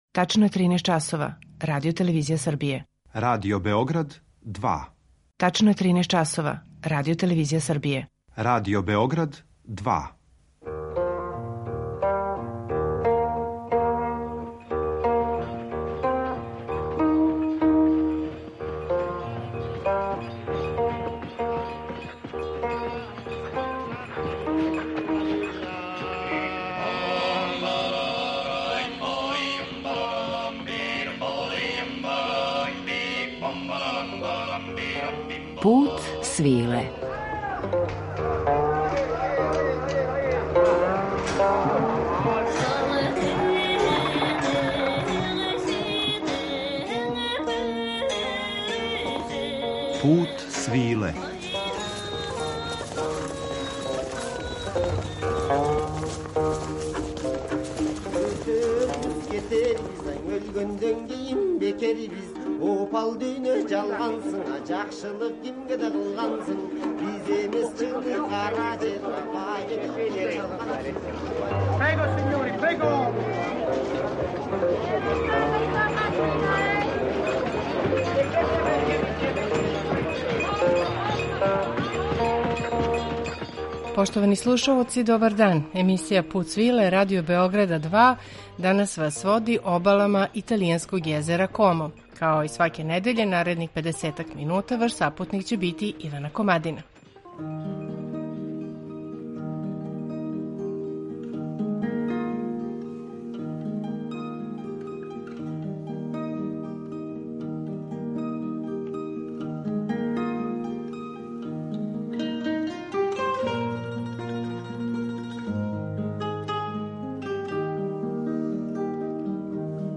У Путу свиле обале и воде Кома обилазимо у пратњи ансамбла „I Viulan".